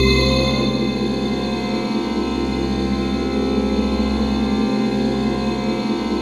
ATMOPAD17 -LR.wav